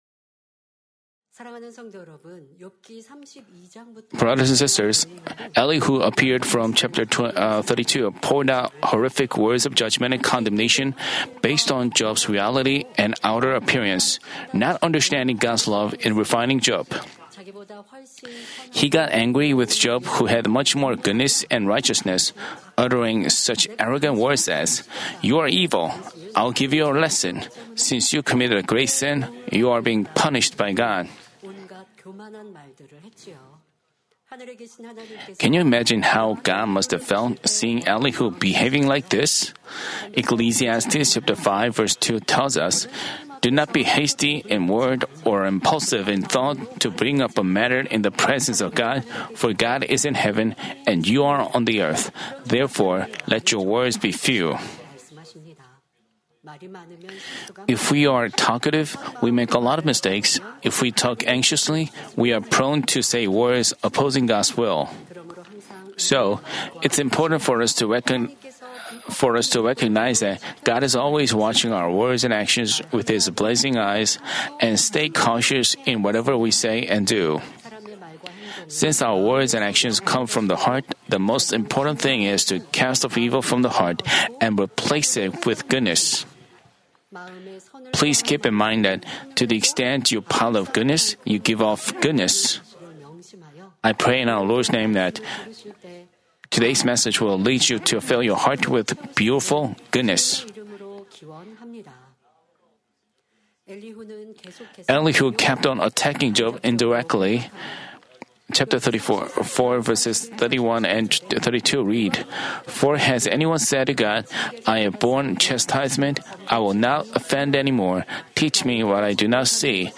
Lecture on Job (102)